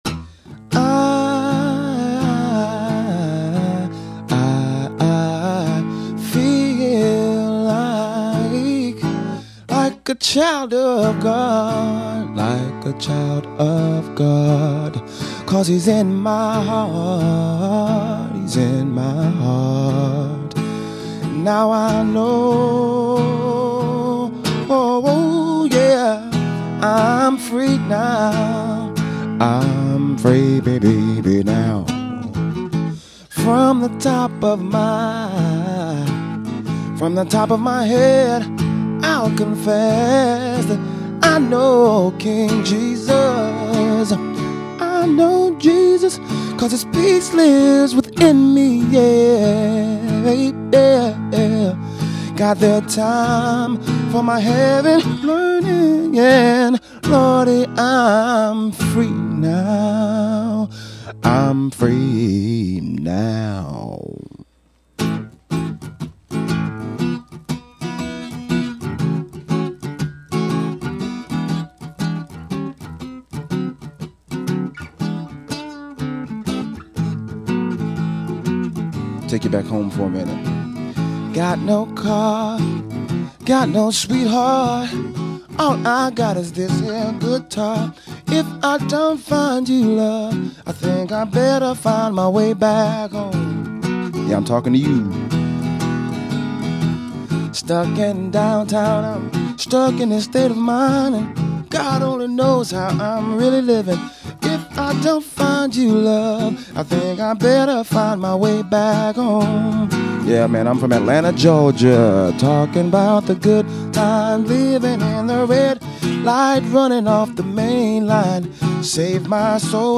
soul
shaker